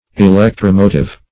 Electro-motive \E*lec`tro-mo"tive\, a.